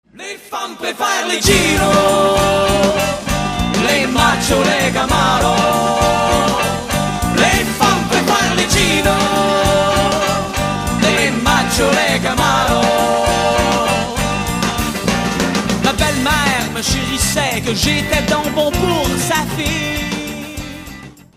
Il nous offrent un rock énergique